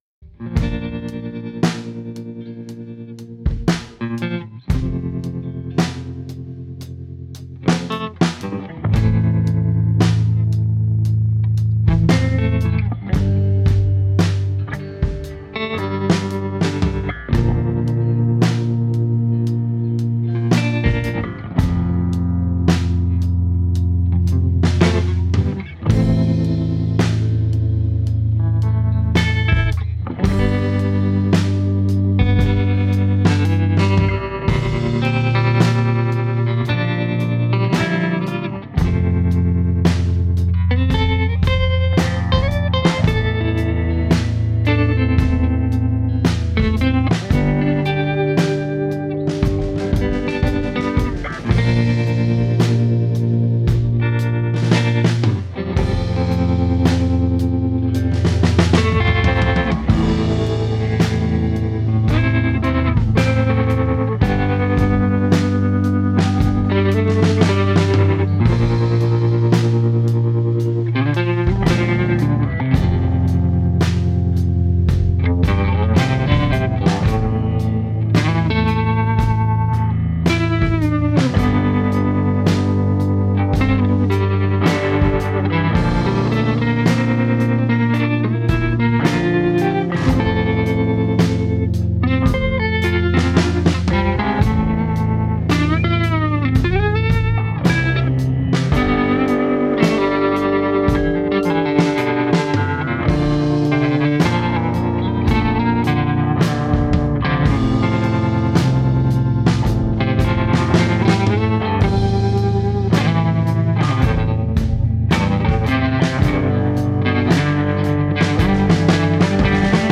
cut a record from hours of free improvisation
Drums
Guitar
Baritone guitar & Bass